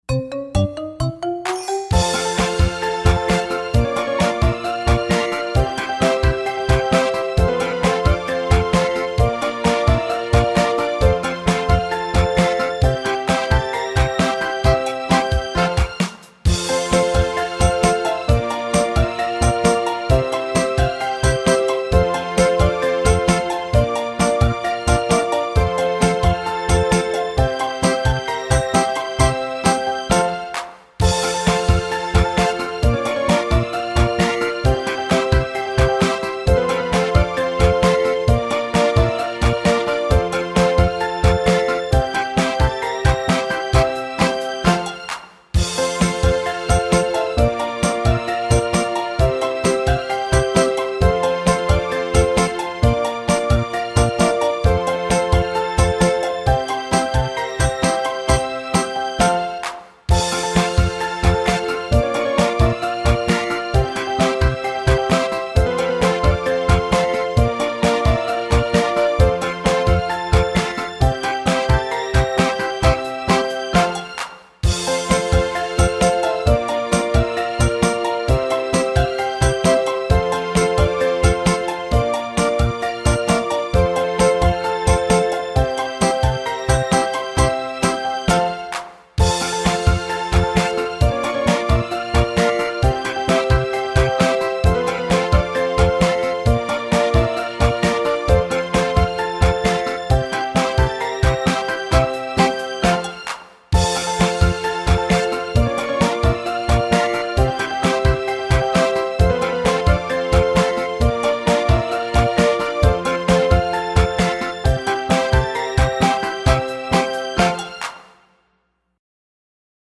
（歌声あり）